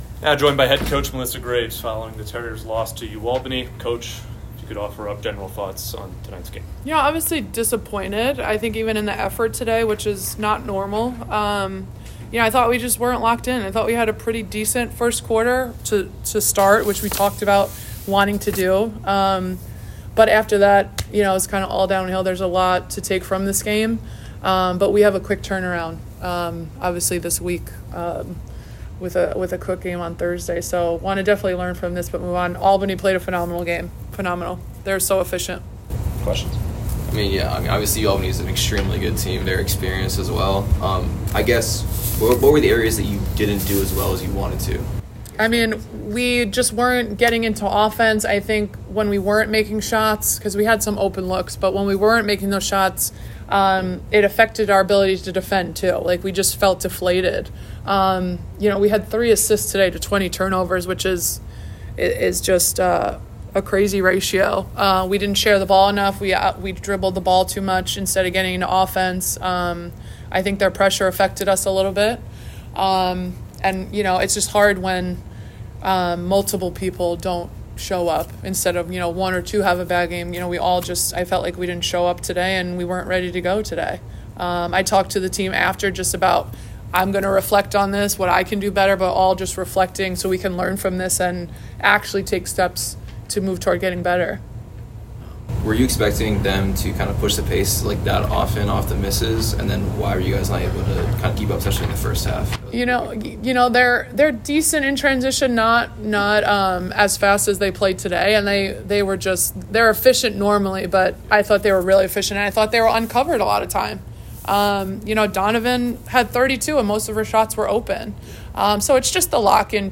WBB_UAlbany_Postgame.mp3